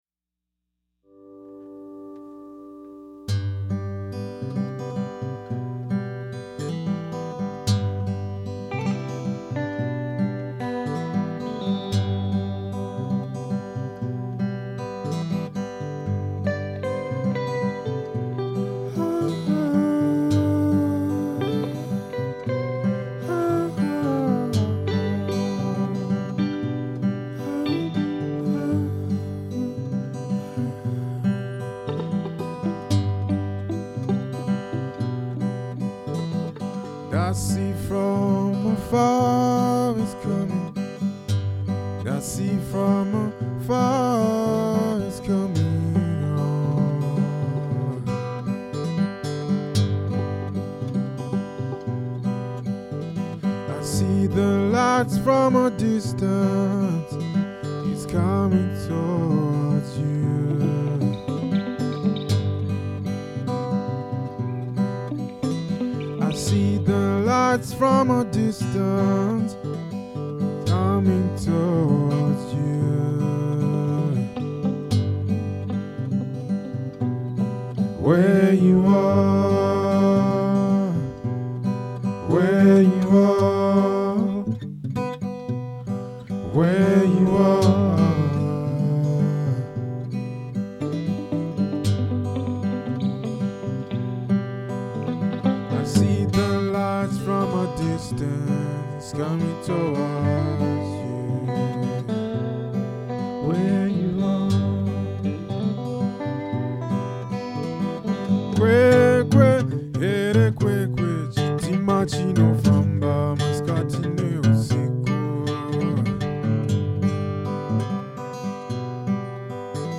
I have a simple little recording setup.
For this recording I used a Sure Beta58 to record the vocals and a Sure 57 to record the guitar and micd the amp with the 57 for the electric guitar as well. I think I got a decently clean recording. I put the cakewalk compressor on all the tracks , but didn't fidget much with the controls.